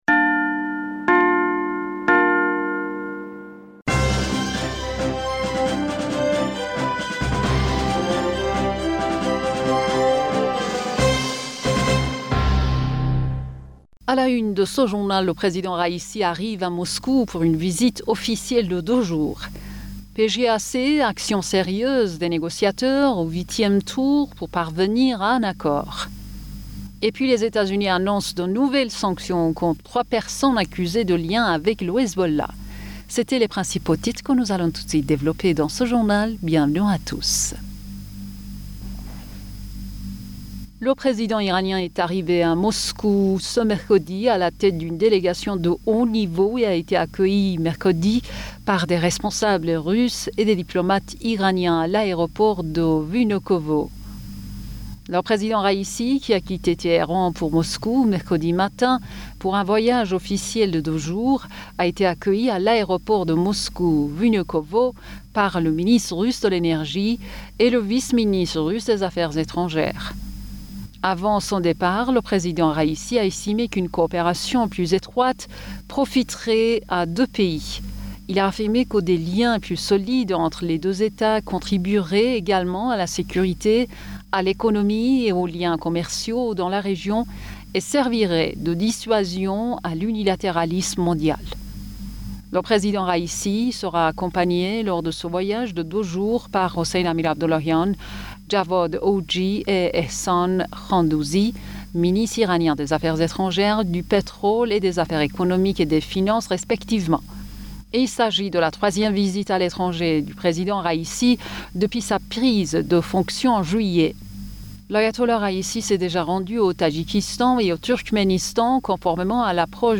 Bulletin d'information Du 19 Janvier 2022